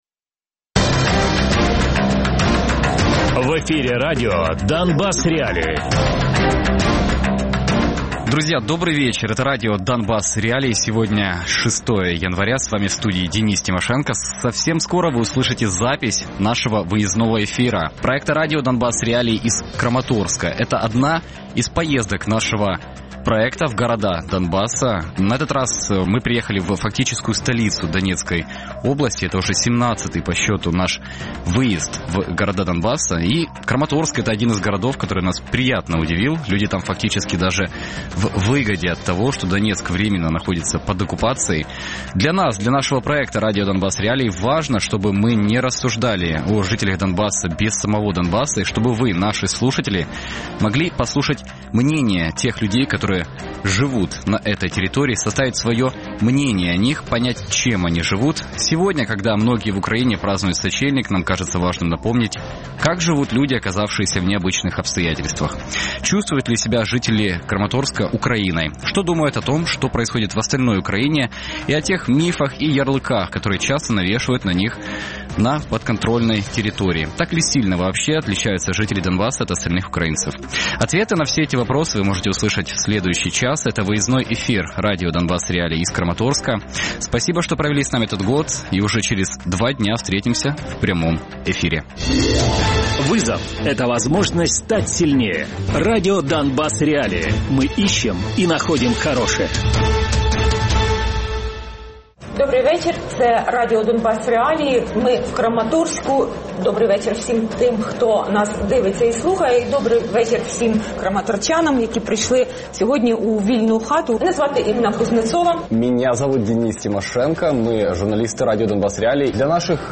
Відкрита студія із жителями Краматорська.